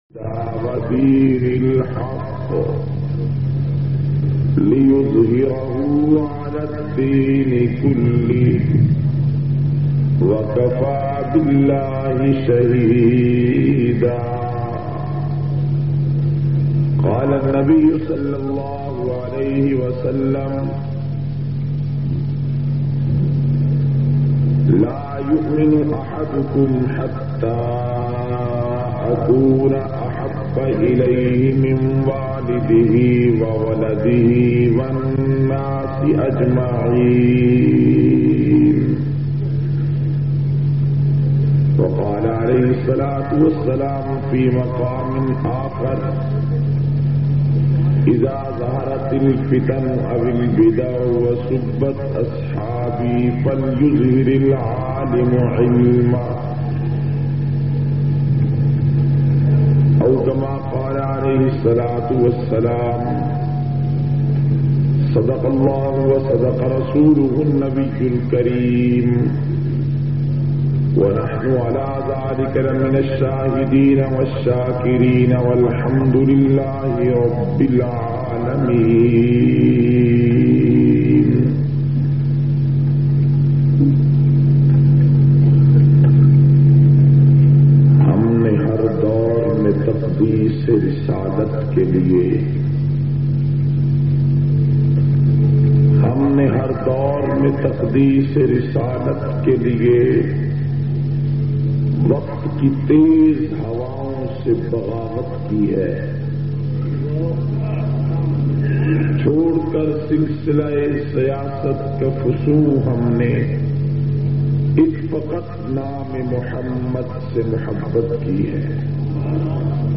697- Sunni Conference-Jehlam.mp3